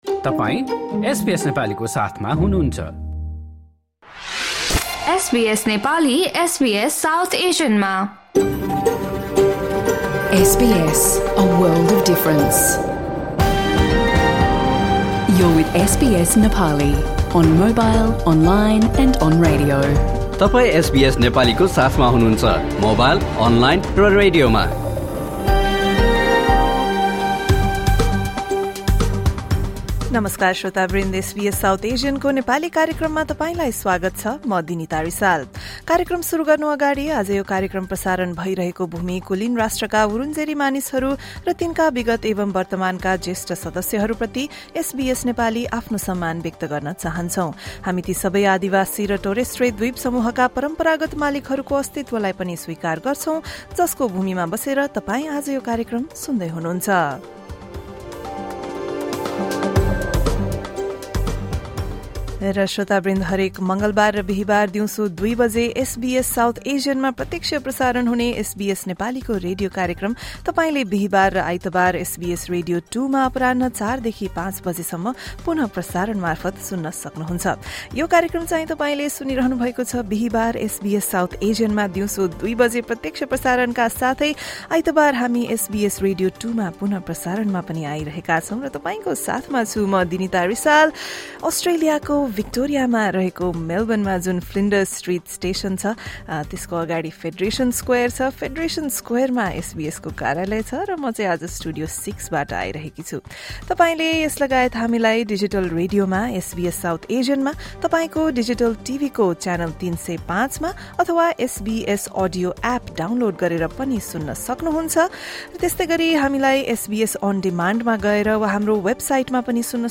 Radio Program: Changes to the Australian visa and advice for new students
SBS Nepali broadcasts a radio program every Tuesday and Thursday at 2 PM on SBS South Asian digital radio and channel 305 on your TV, live from our studios in Sydney and Melbourne.